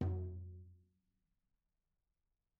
TomL_HitS_v2_rr2_Mid.mp3